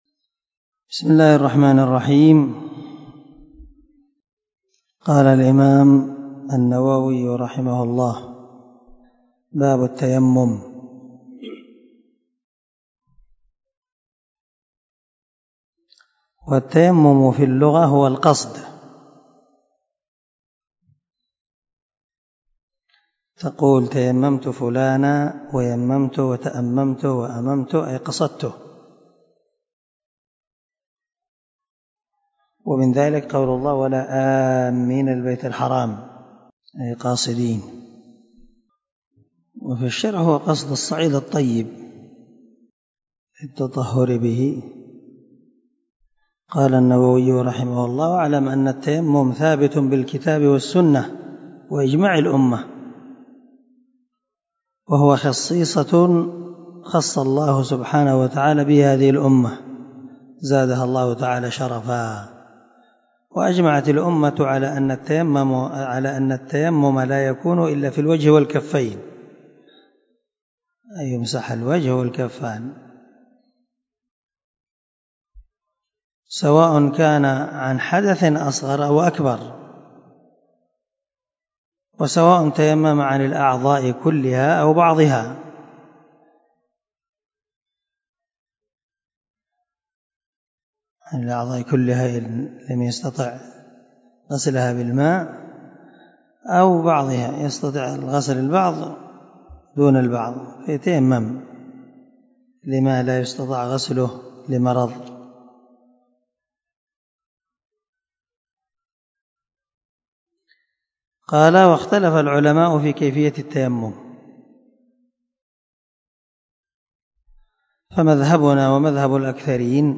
250الدرس 34 من شرح كتاب الحيض حديث رقم ( 367 – 368 ) من صحيح مسلم
دار الحديث- المَحاوِلة- الصبيحة.